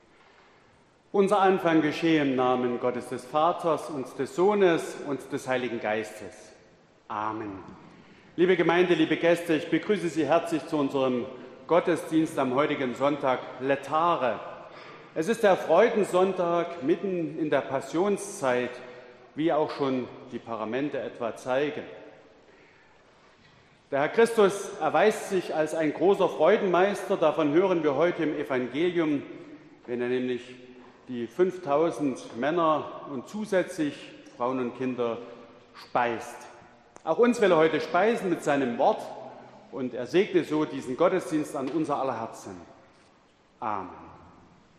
Gottesdienst am 14.03.2021
Orgel und Posaune